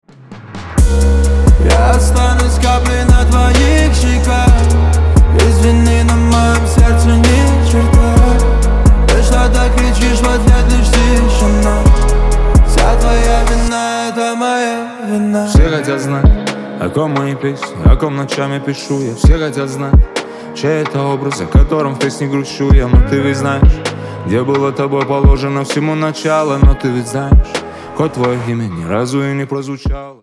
Поп Музыка
грустные # спокойные